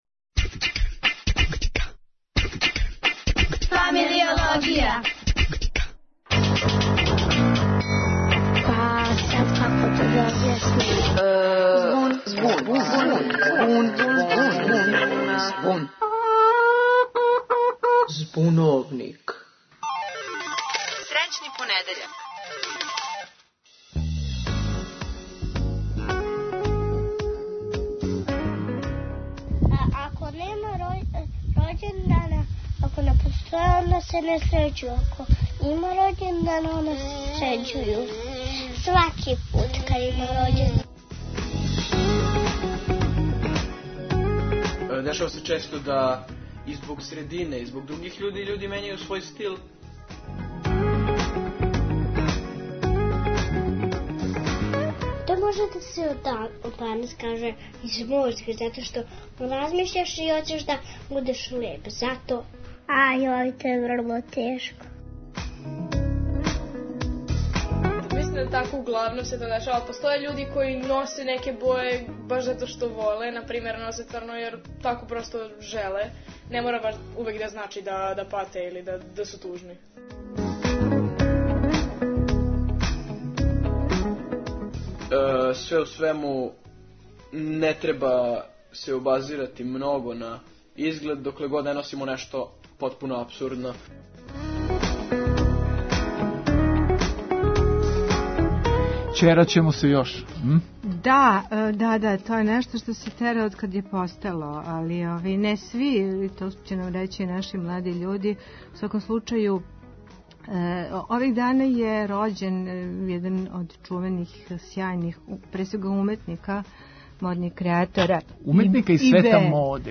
У трагању за одговорима нам помажу деца, млади и једна од наших најсјајнијих личности из света моде